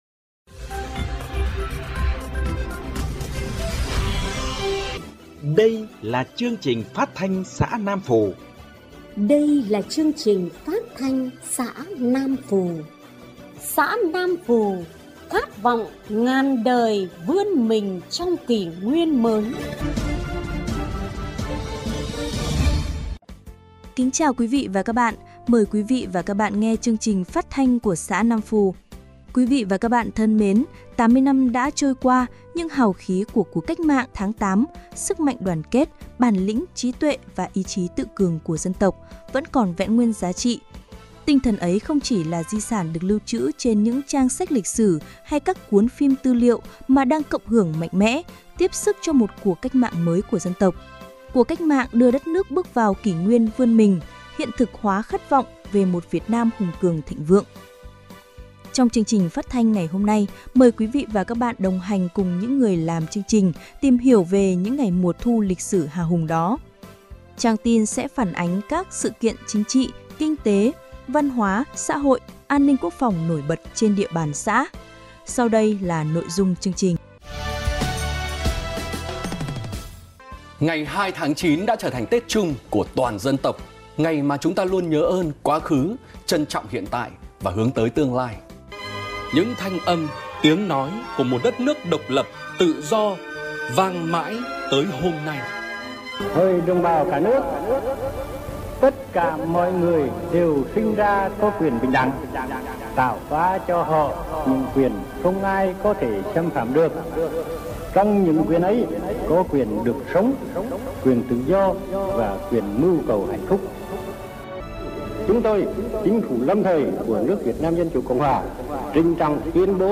Chương trình phát thanh xã Nam Phù ngày 01/9/2025